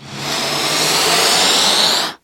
VEH1 Reverse - 08.wav